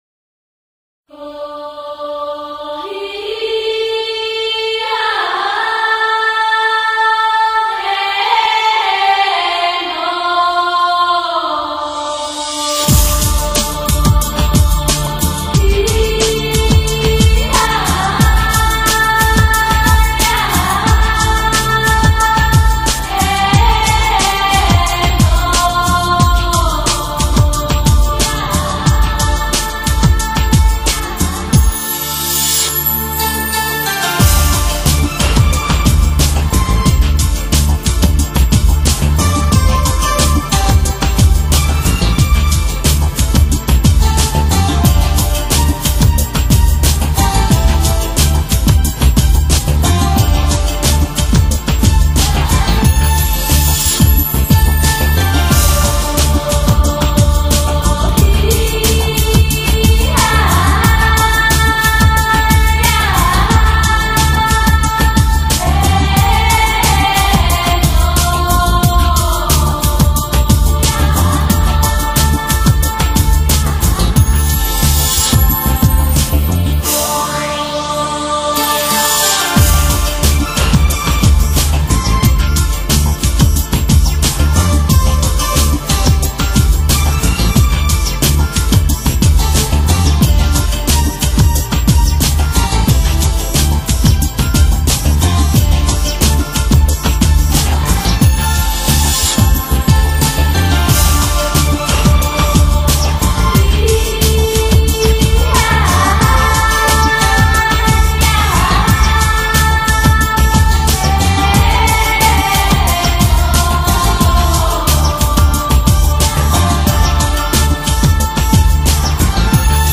[18/1/2010][纯真美声]Innocent Voice ——东欧民族音乐